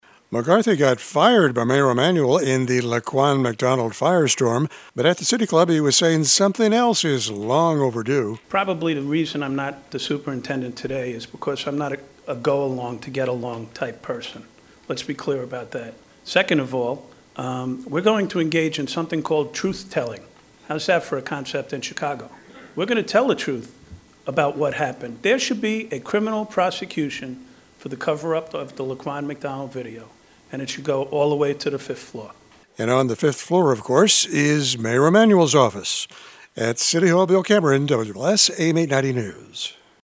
McCarthy got fired by Mayor Emanuel in the Laquan McDonald firestorm but at the City Club he was saying something else is long overdue.